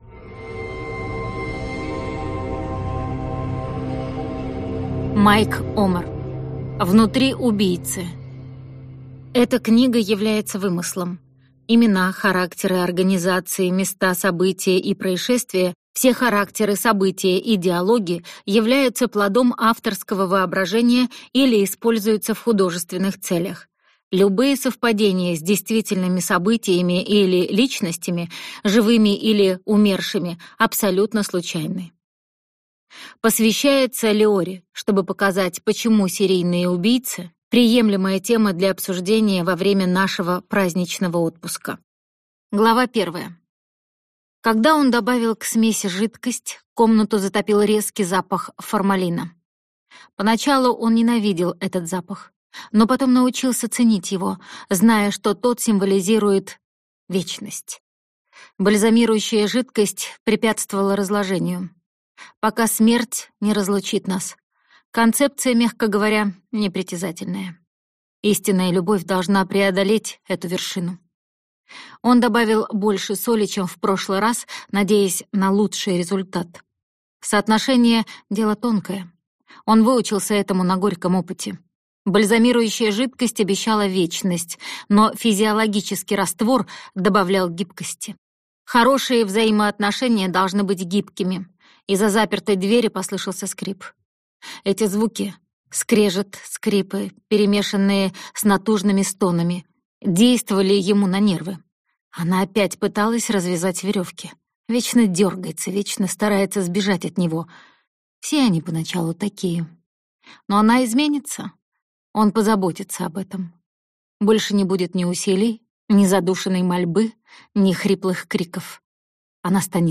Прослушать фрагмент аудиокниги Внутри убийцы Майк Омер Произведений: 8 Скачать бесплатно книгу Скачать в MP3 Скачать в TXT Скачать в PDF Скачать в EPUB Вы скачиваете фрагмент книги, предоставленный издательством